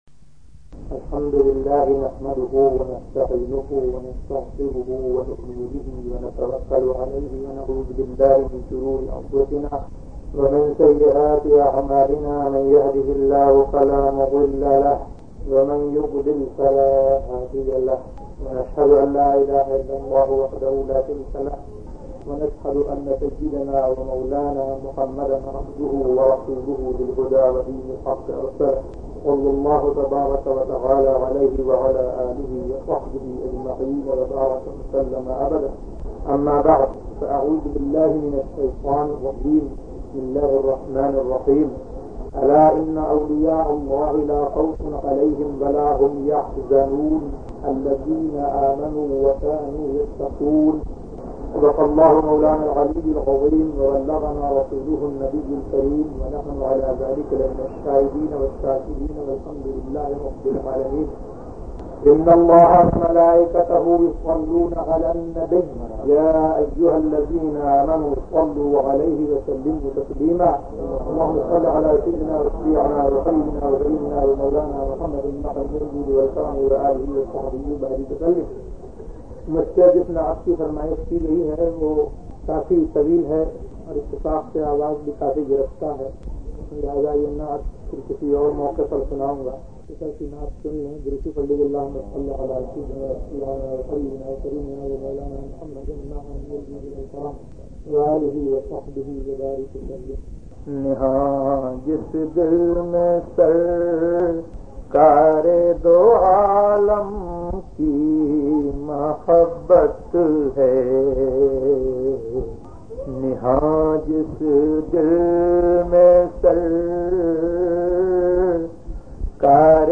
Hazrat First Speech
تقاریر
hazrat-first-speech.mp3